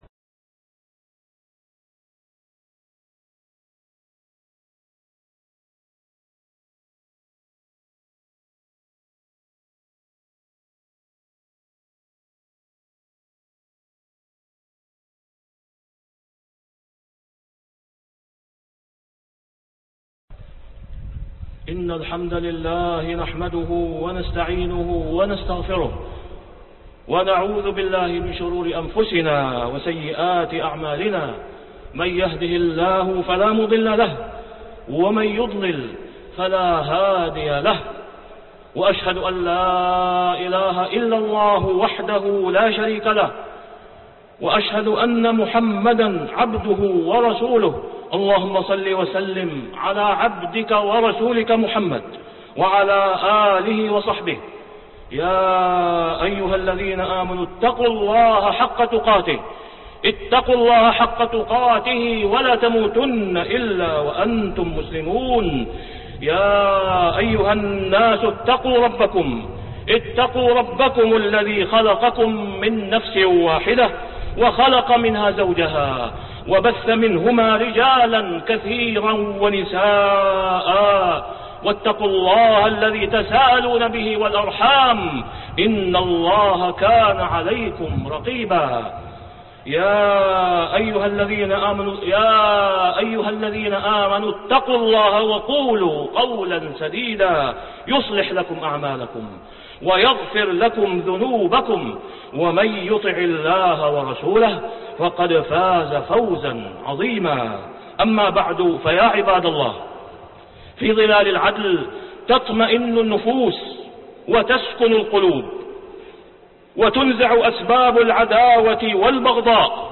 مراعاة أجراء العمل (5/2/2010) خطبة الجمعة من الحرم المكي - الشيخ أسامة بن عبد الله خياط